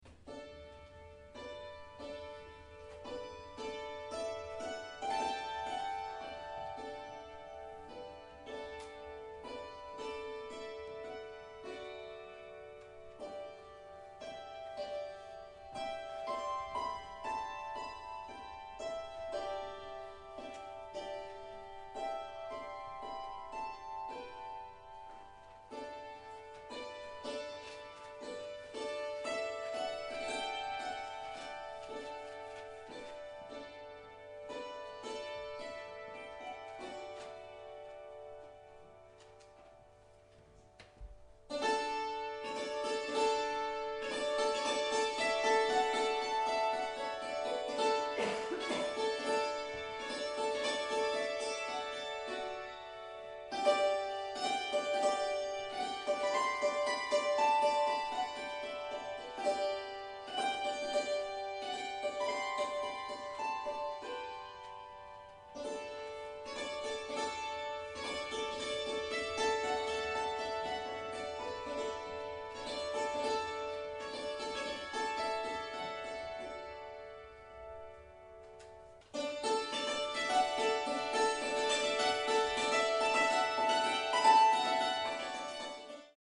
Steirisches Hackbrett